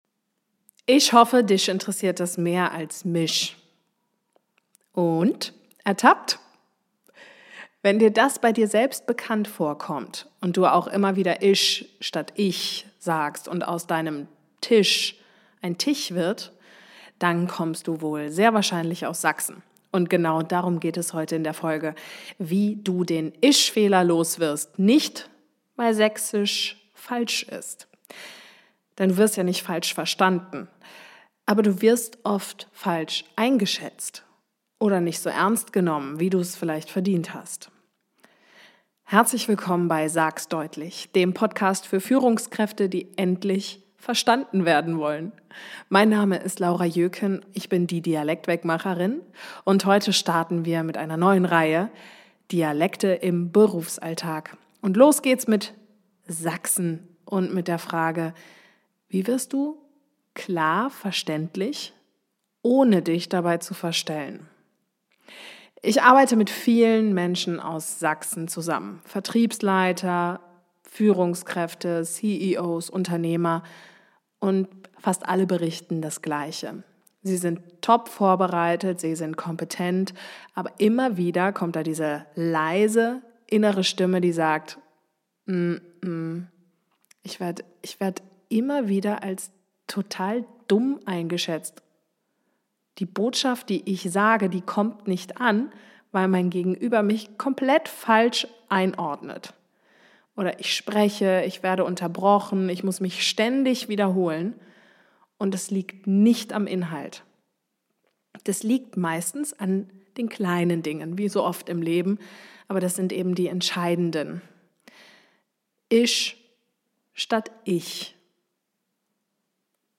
– eine Mini-Übung zum Mitmachen